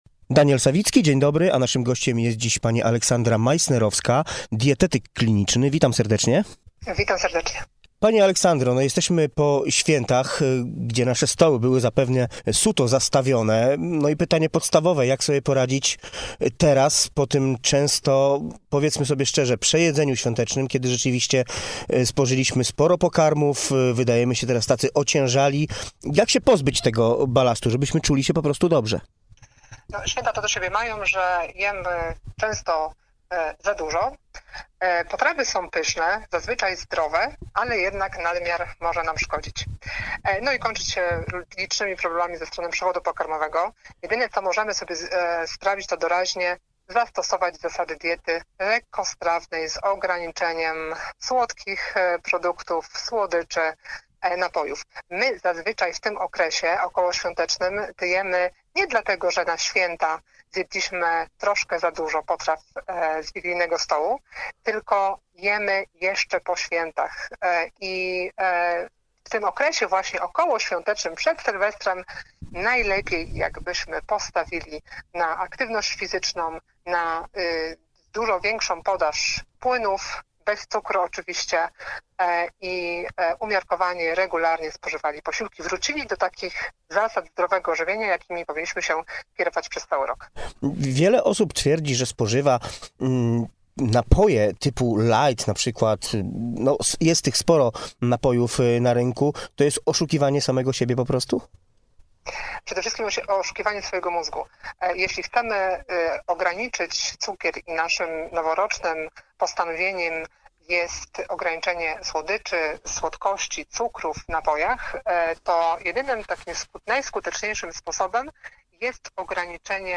Z dietetykiem klinicznym rozmawia